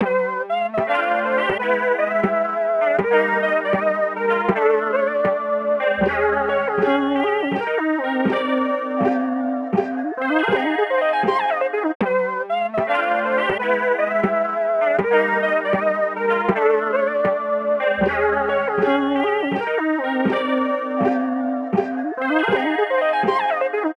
SO_MY_160_melodic_loop_descenion_Abmaj